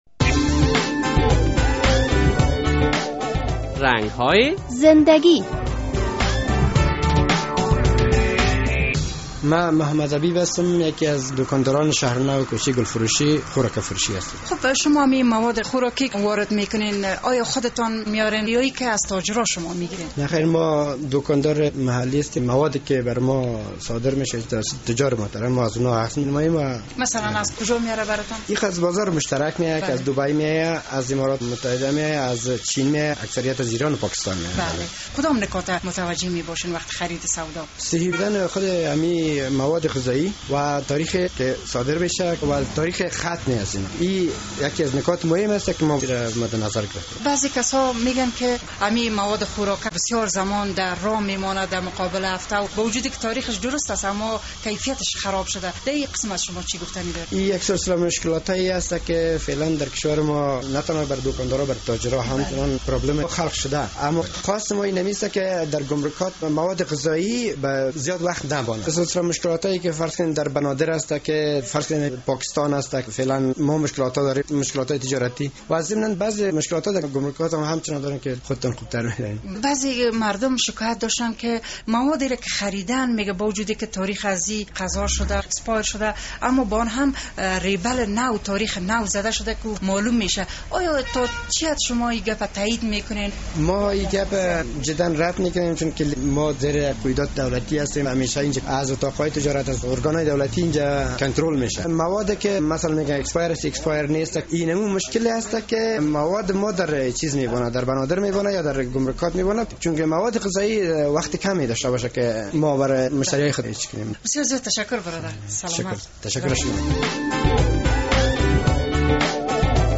در مورد کار و بارش با خبرنگار رادیو آزادی چنین توضیحات داد: